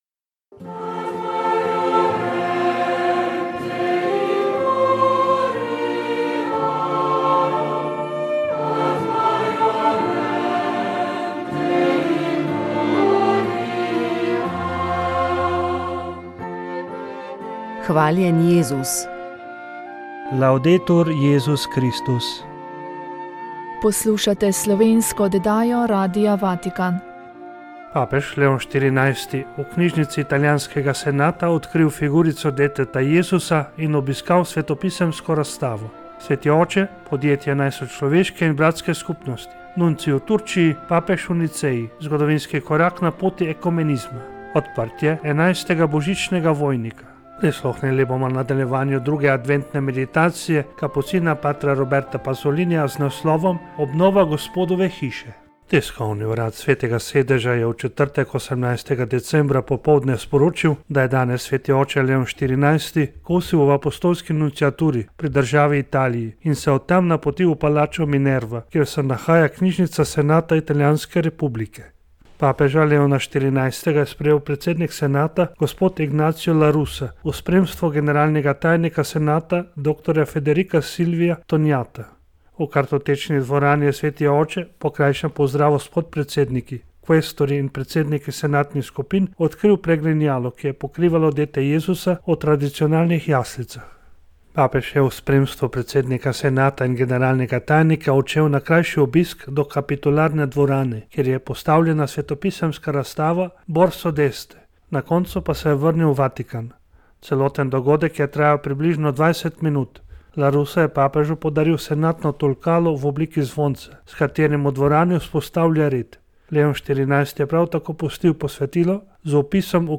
Rožni venec
Molil so radijski sodelavci.